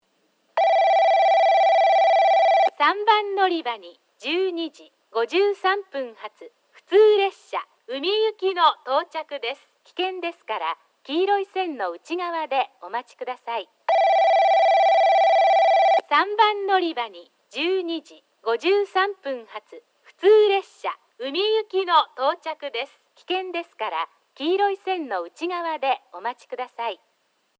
3番のりば接近放送（宇美行き）
※香椎線ホームの接近ベルは省略しております。